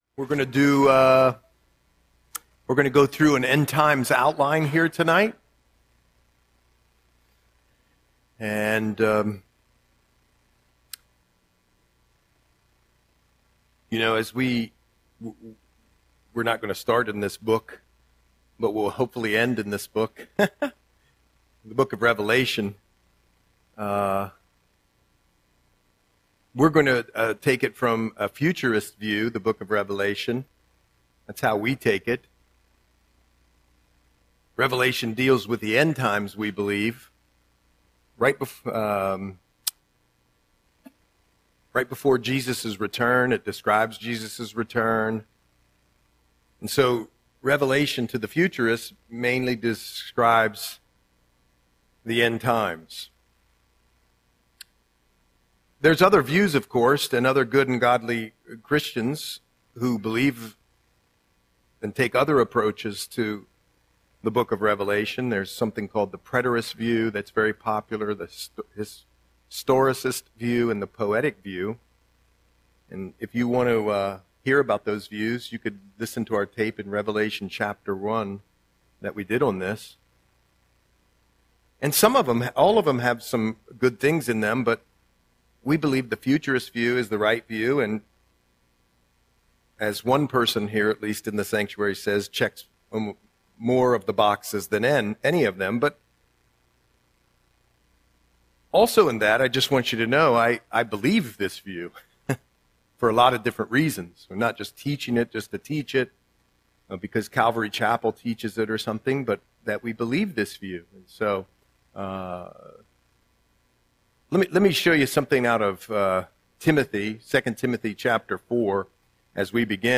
Audio Sermon - February 4, 2026